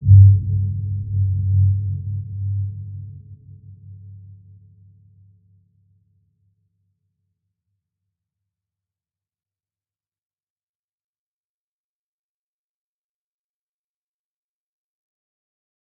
Dark-Soft-Impact-G2-p.wav